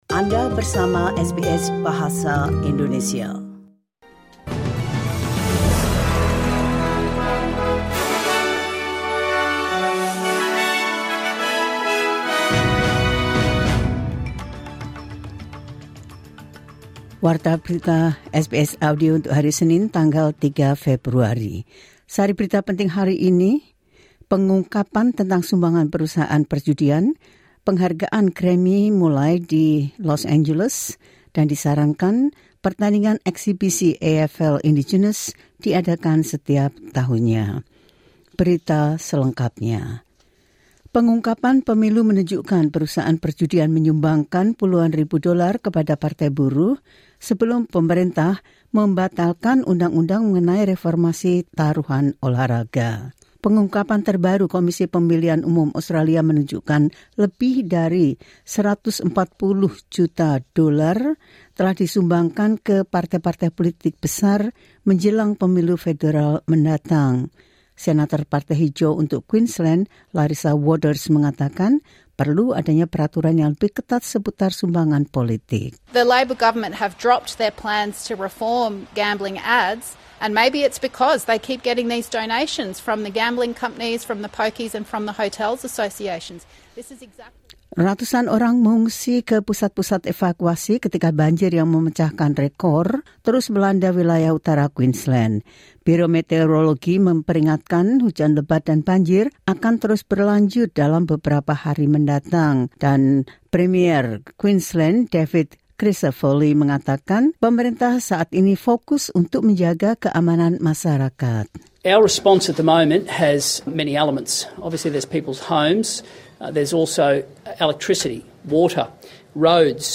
Latest News SBS Audio Indonesian Program – 03 February 2025